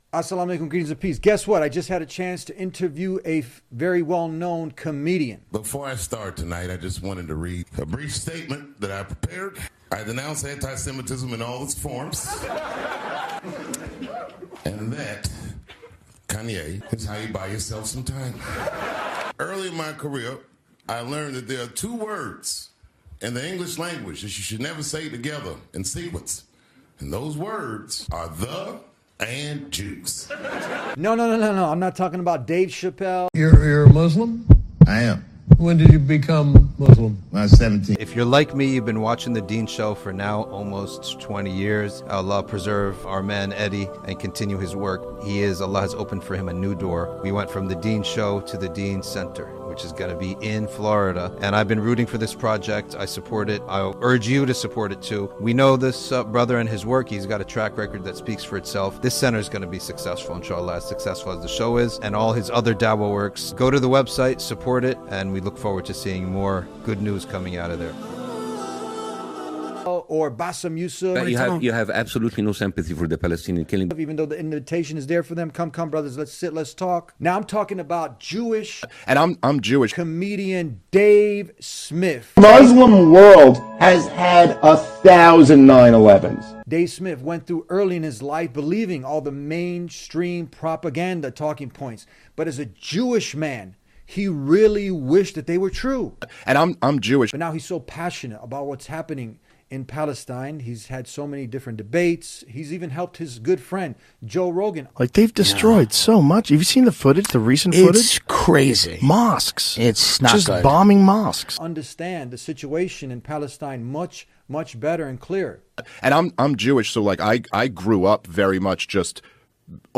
In a rare and groundbreaking conversation, Jewish comedian and political commentator Dave Smith sits down with a Muslim host to discuss the Israeli-Palestinian conflict with a level of honesty almost never seen on mainstream media.